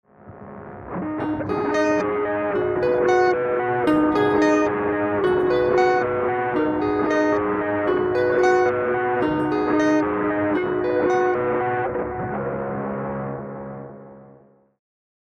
Enregistrer directement dans une table yamaha MG-12/4, avec une carte ECHO MIA MIDI.
Le son est neutre,sans traitement.
EFFECT LOOP-Son delay sans effet ( avec BIG MUFF ) :
PT-80-LOOP-Big muff WET-.mp3